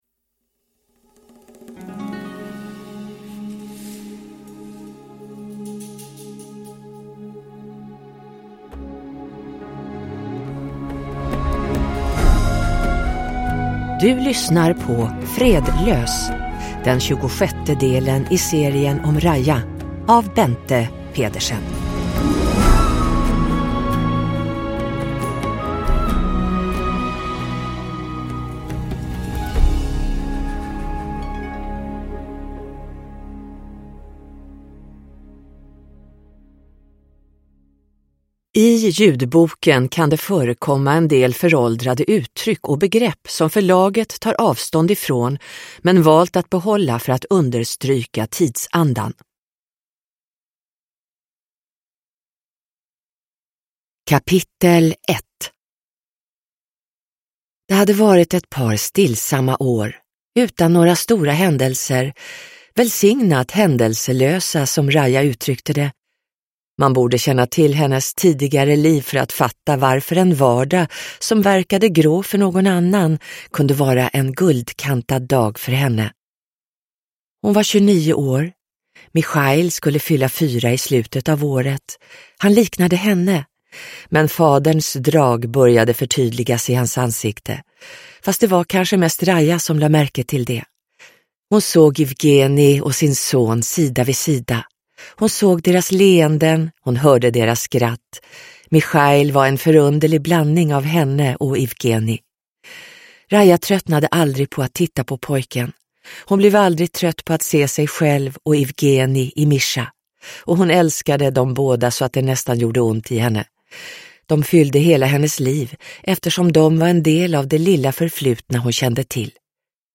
Fredlös – Ljudbok – Laddas ner